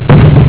cannon.wav